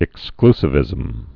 (ĭk-sklsĭ-vĭzəm)